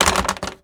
paper.wav